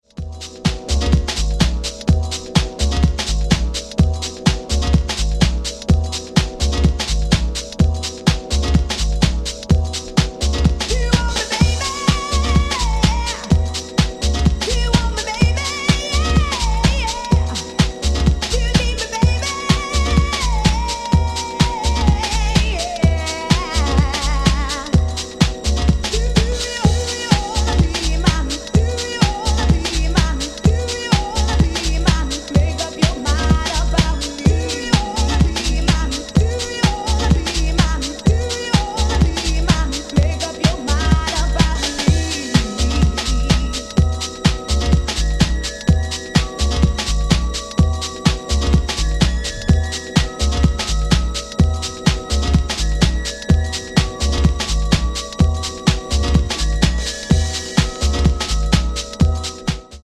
the deep house sound of New York in the early 90s